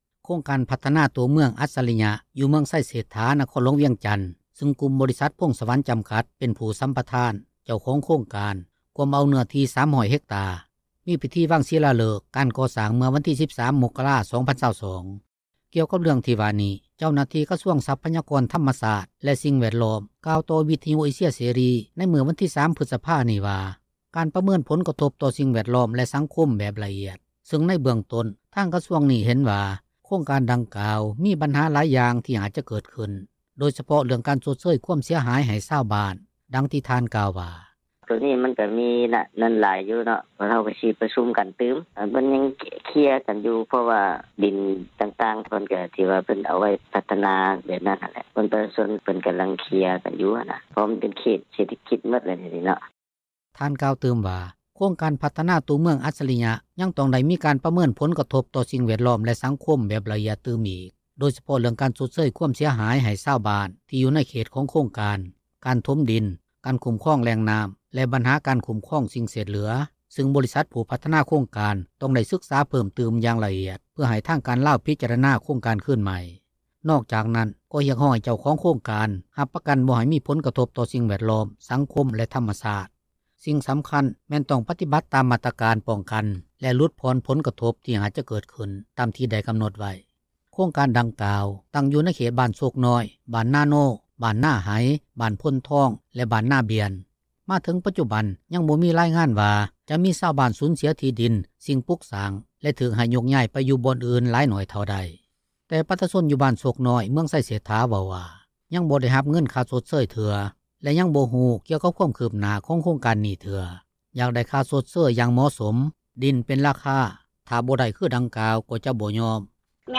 ດັ່ງທີ່ແມ່ຍິງ ນາງນຶ່ງເວົ້າວ່າ: